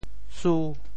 潮州拼音“su5”的详细信息
国际音标 [su]